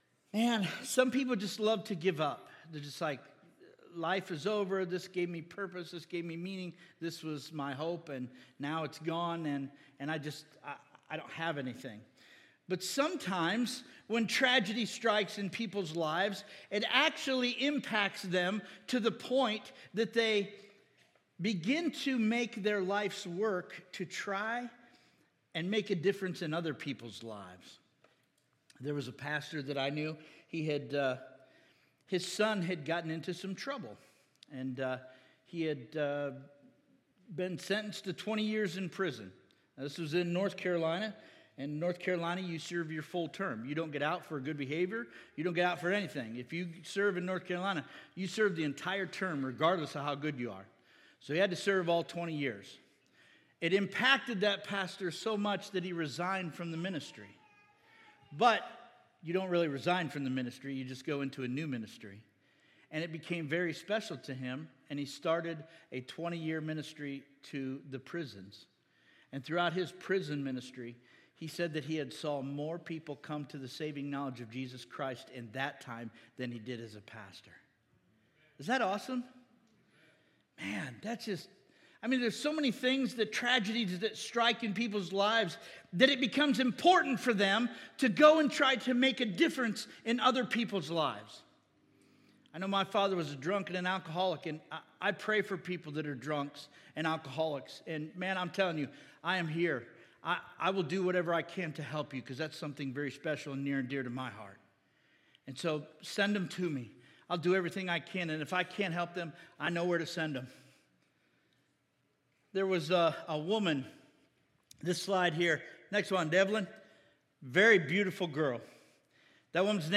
Sermons Archive - Page 6 of 51 -